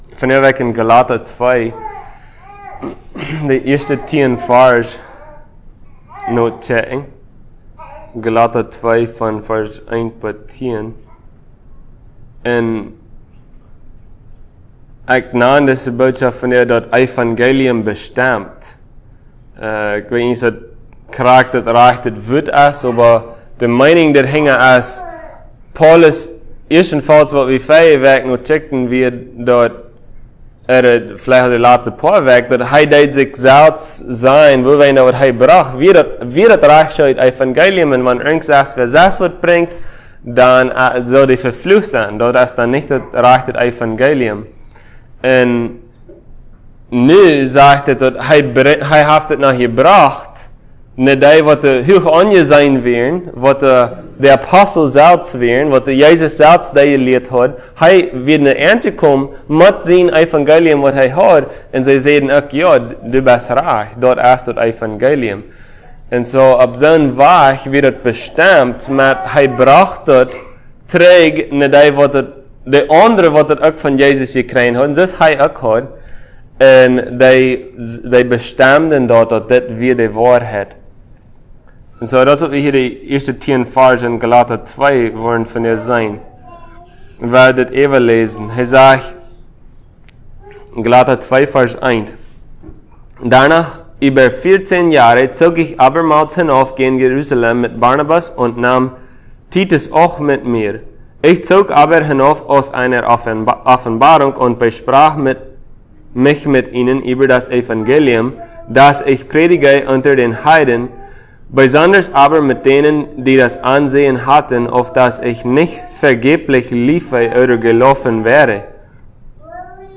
Passage: Galatians 2:1-10 Service Type: Sunday